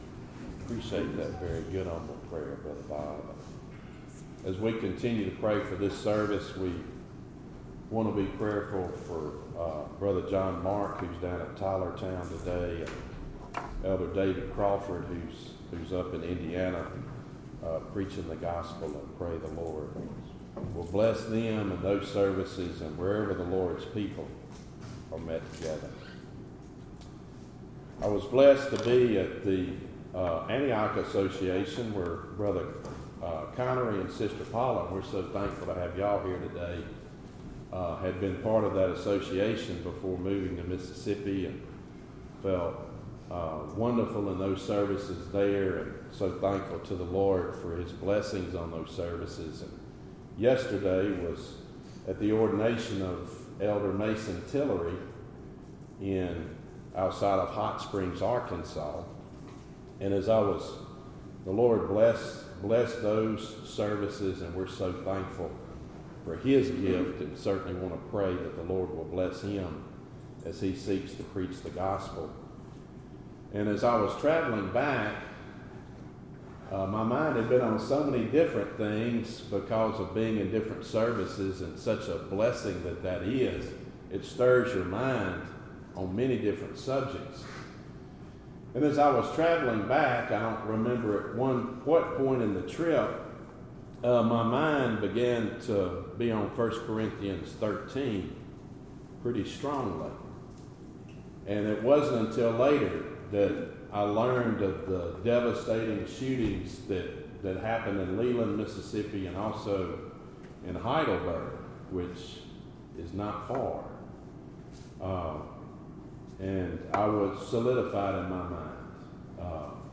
Topic: Sermons